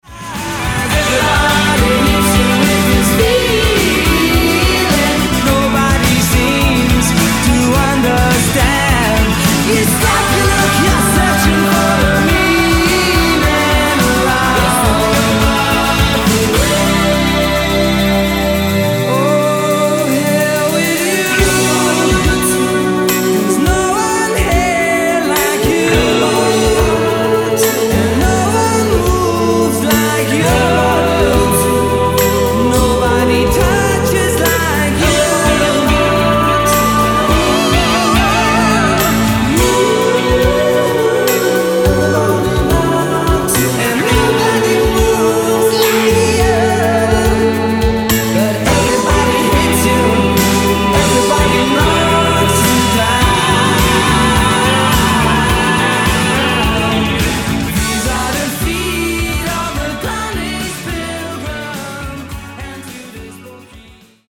the newly-formed choir recording their own version